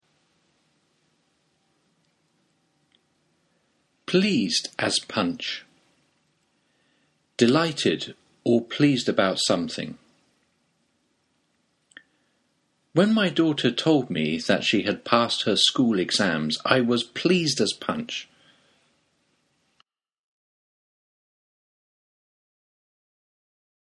ネイティブによる発音は下記のリンクをクリックしてください。
Pleasedaspunch.mp3